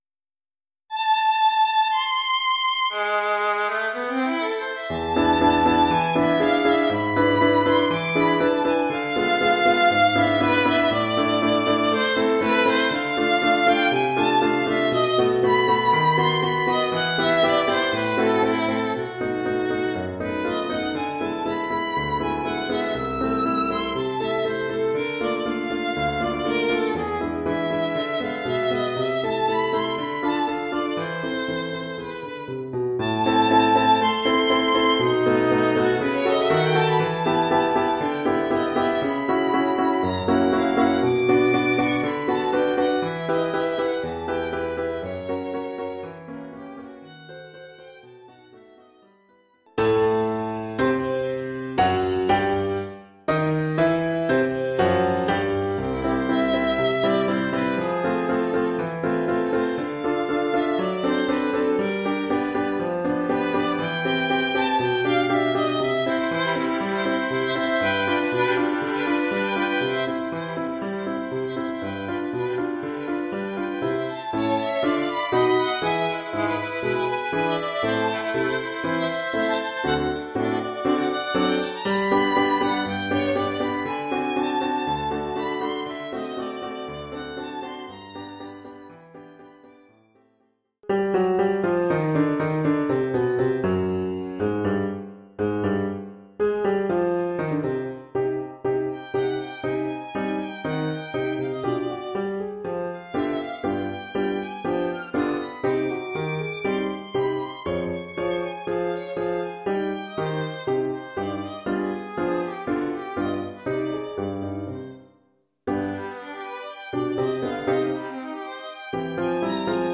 1 titre, violon et piano : conducteur et partie de violon
Oeuvre pour violon avec accompagnement de piano.